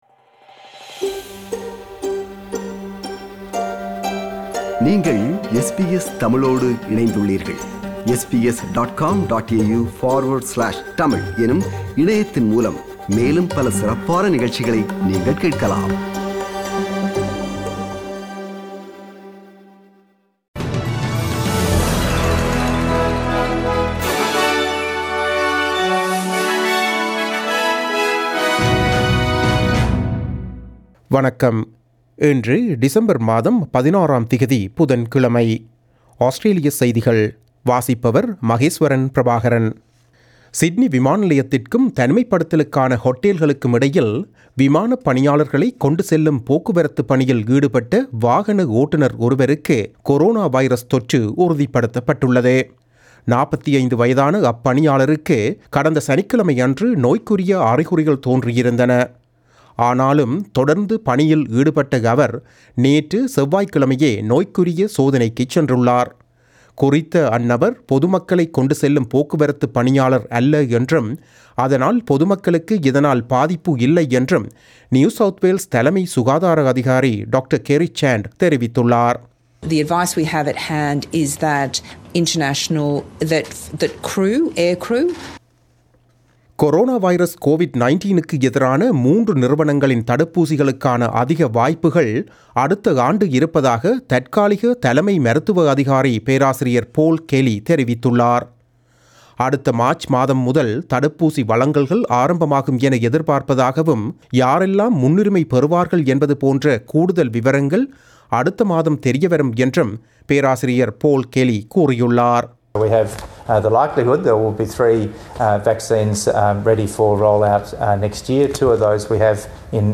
Australian news bulletin for Wednesday 16 December 2020.